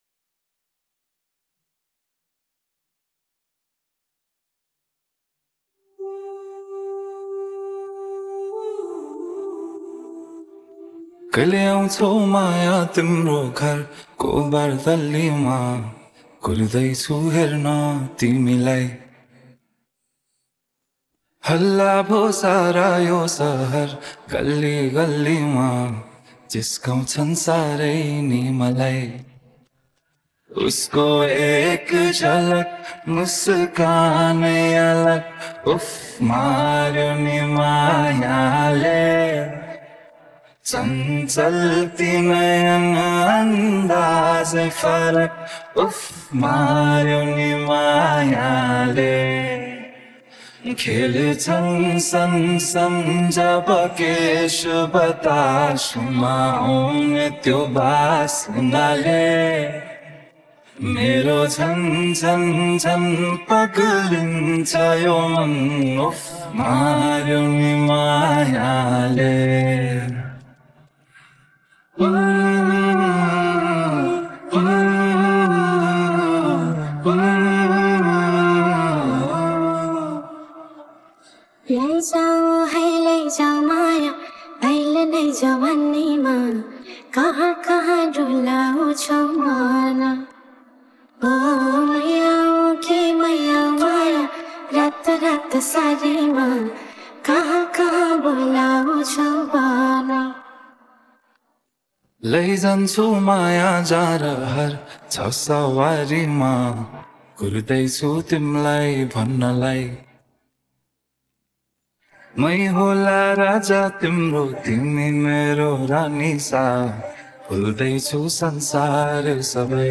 Голосовая часть